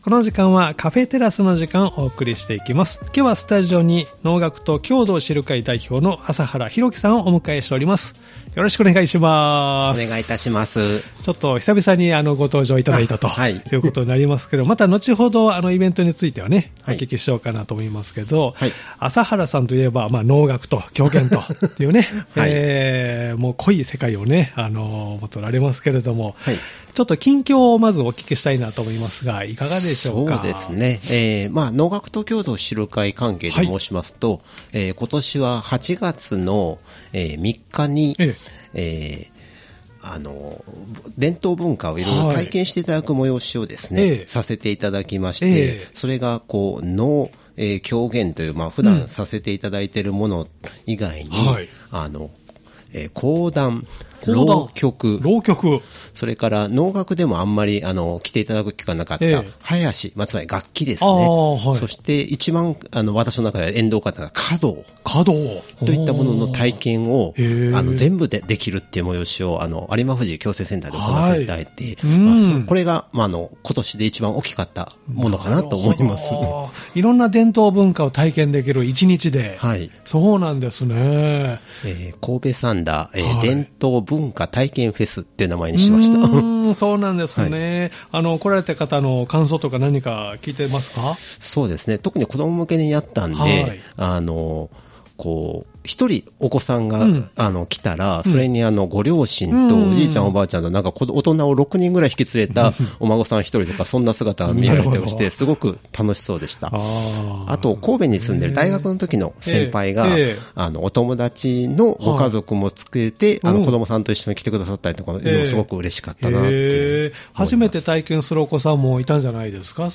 様々なゲストをお迎えするトーク番組「カフェテラス」（再生ボタン▶を押すと放送が始まります）